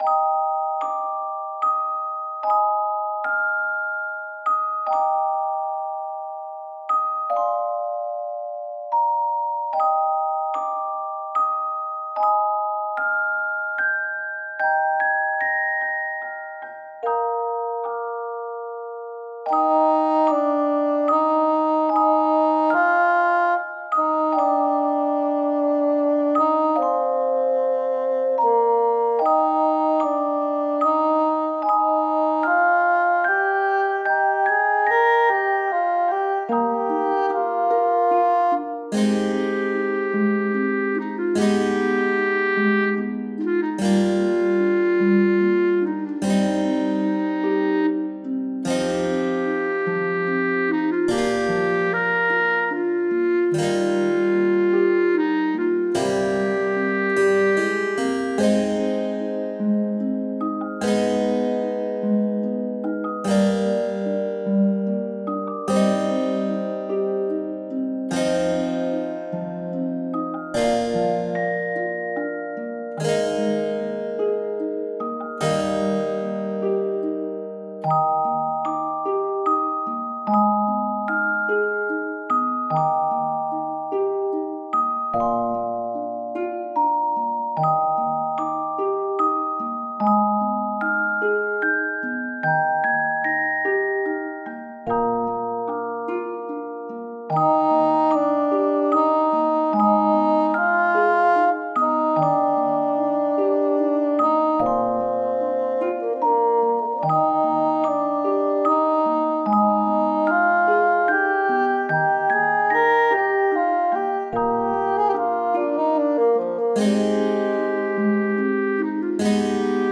浄化されそうな感じの曲。